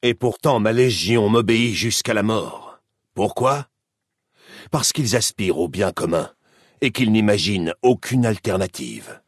Caesar expliquant pourquoi sa Légion l'obéit dans Fallout: New Vegas.
Catégorie:Dialogue audio de Fallout: New Vegas